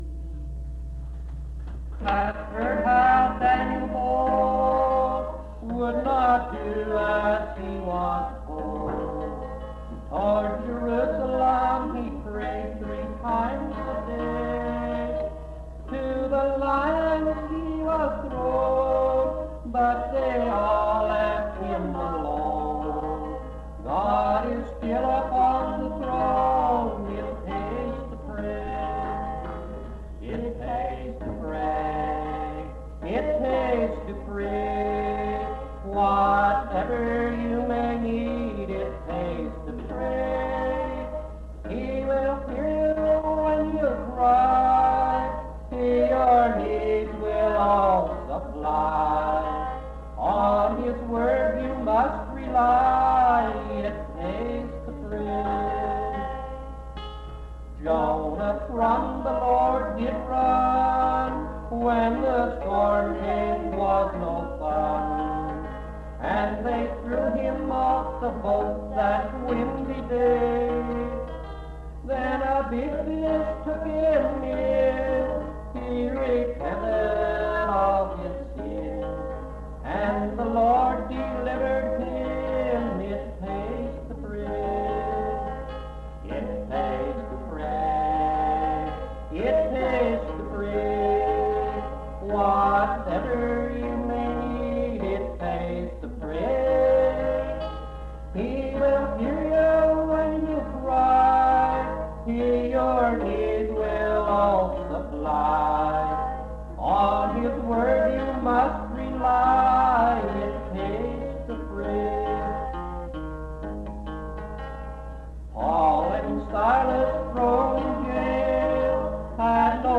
It Pays to Pray Item af4364d7fa856e12602bf7be139fe4adcf5f19b3.mp3 Title It Pays to Pray Creator unidentified couple Description This recording is from the Miscellaneous Collection, track 159I.